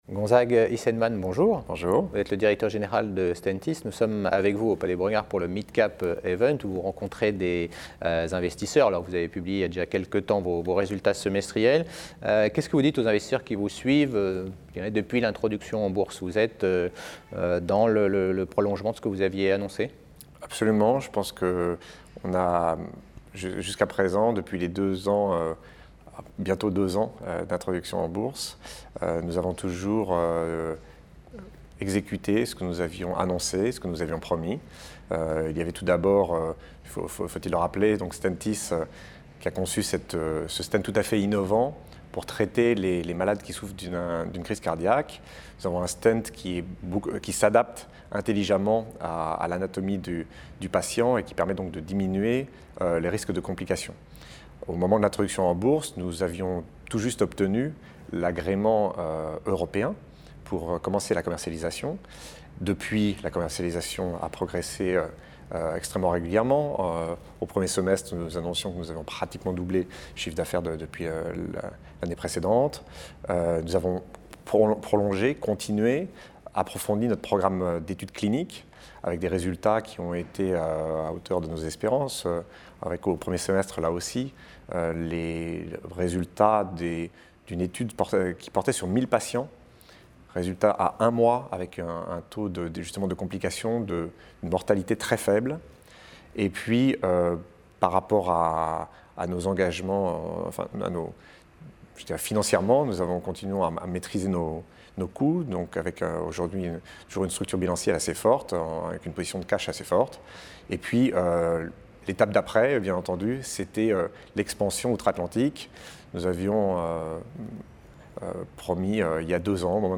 Interview réalisé lors du Midcap Event organisé par CF&B Communication pour le compte de NYSE Euronext